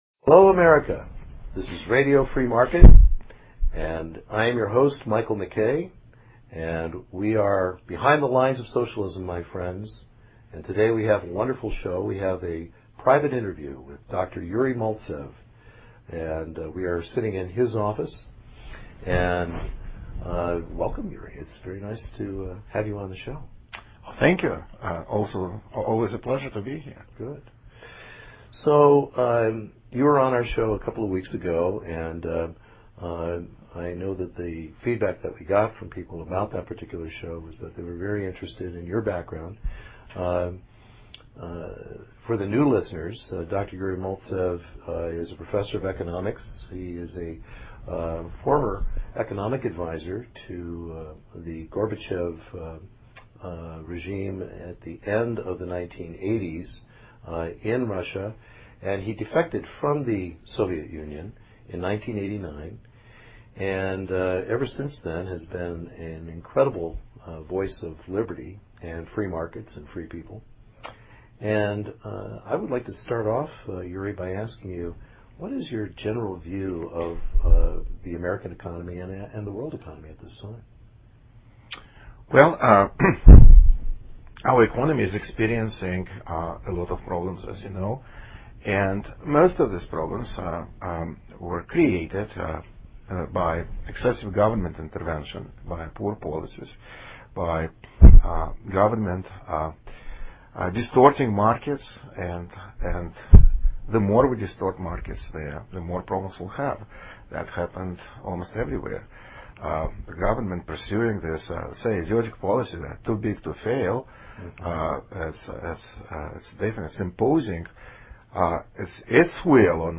A unique in-person conversation